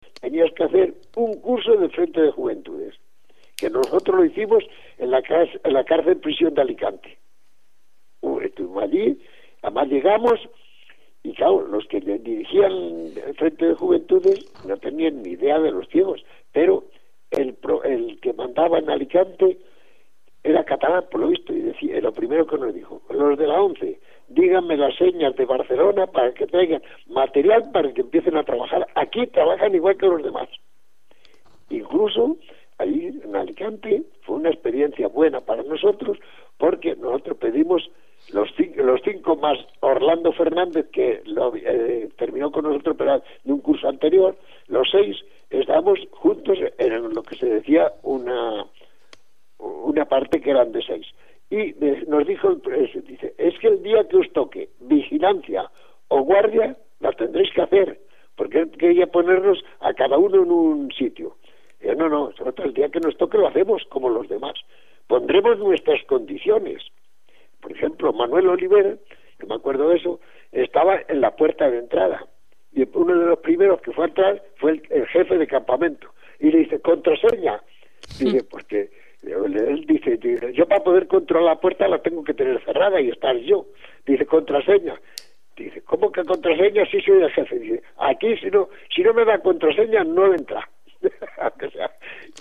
aquel fragmento de vida ya muy lejana formato MP3 audio(1,76 MB), con tono de expectación.